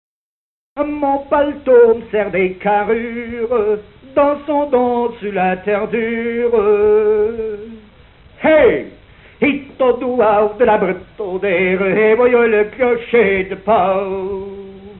Genre énumérative
Chanson
Pièce musicale inédite